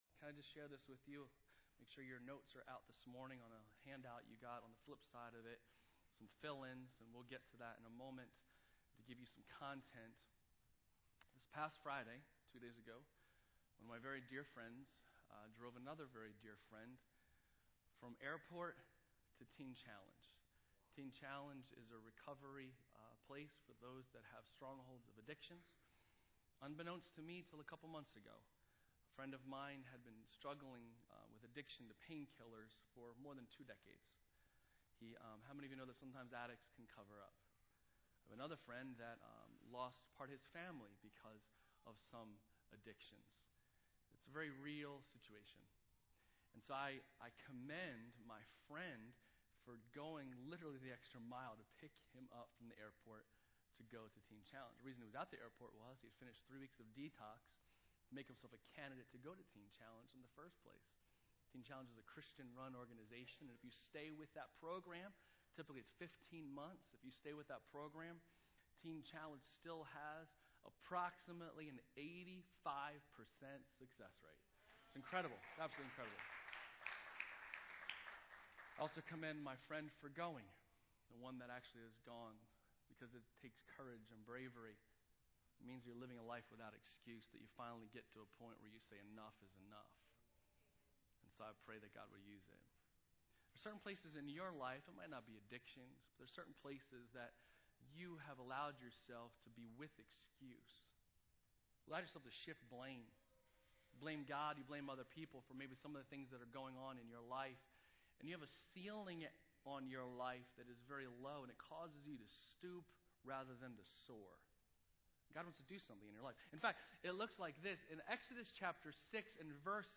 Sermons | Bethany Church